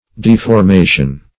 Deformation \Def`or*ma"tion\, n. [L. deformatio: cf. F.